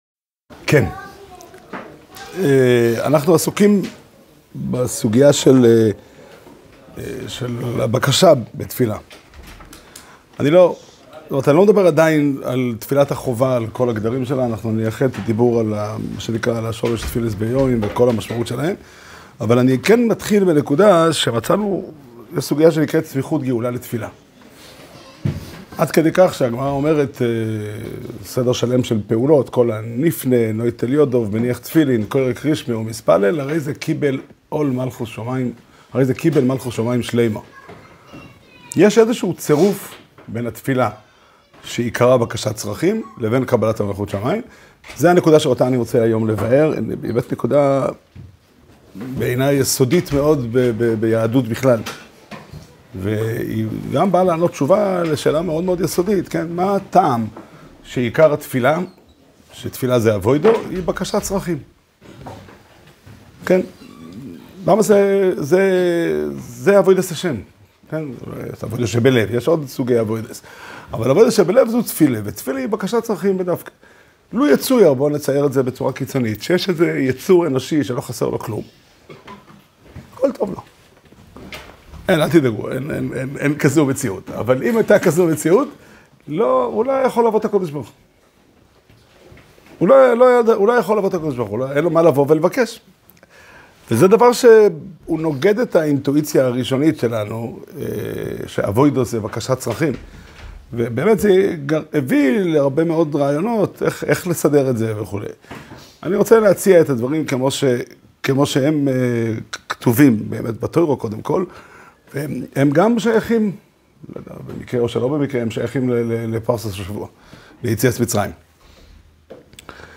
שיעור שנמסר בבית המדרש פתחי עולם בתאריך א' שבט תשפ"ה